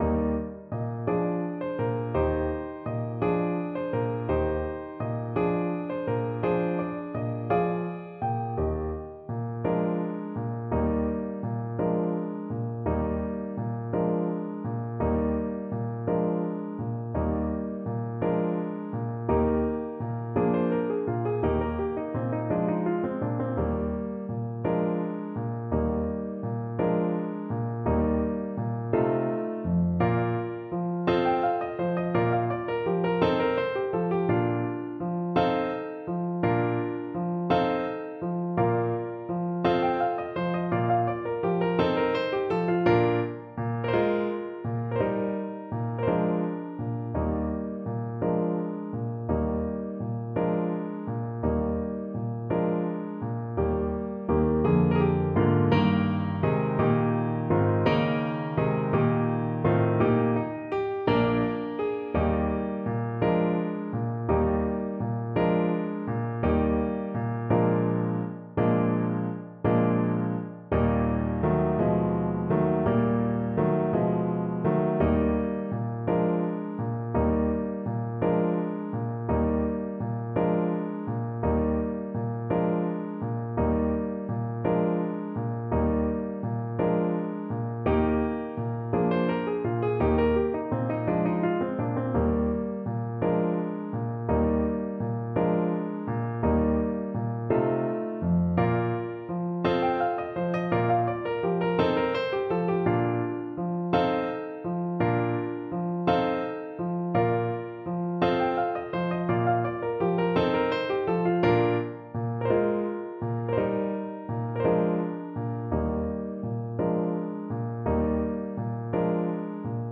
Classical Gounod, Charles Sérénade Trumpet version
Moderato quasi allegretto .=c.56
Eb major (Sounding Pitch) F major (Trumpet in Bb) (View more Eb major Music for Trumpet )
6/8 (View more 6/8 Music)
Classical (View more Classical Trumpet Music)